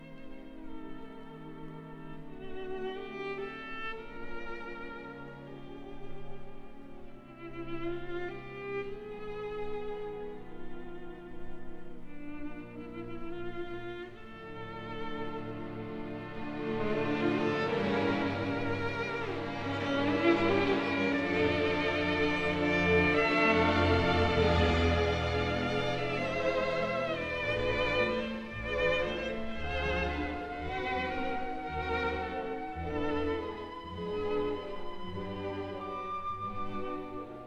at No. 1 Studio, Abbey Road, London